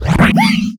alianhit1.ogg